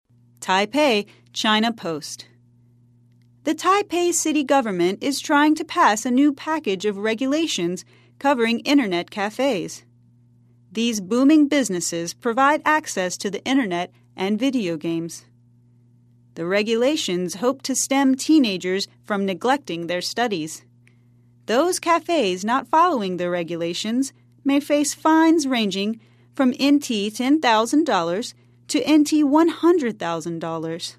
在线英语听力室赖世雄英语新闻听力通 第51期:网咖条例的听力文件下载,本栏目网络全球各类趣味新闻，并为大家提供原声朗读与对应双语字幕，篇幅虽然精短，词汇量却足够丰富，是各层次英语学习者学习实用听力、口语的精品资源。